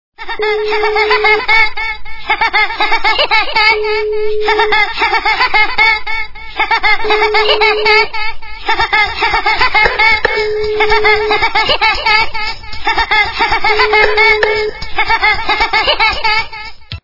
» Звуки » Смешные » Смех - лилипута
При прослушивании Смех - лилипута качество понижено и присутствуют гудки.
Звук Смех - лилипута